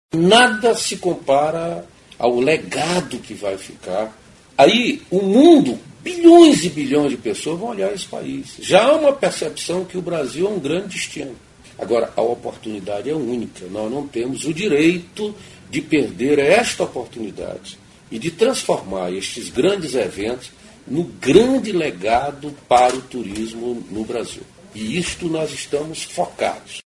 aqui e ouça declaração do ministro Gastão Vieira sobre a importância da Copa para a imagem do Brasil no exterior.